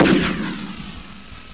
soundeffects
Amiga 8-bit Sampled Voice
rocketartillery.mp3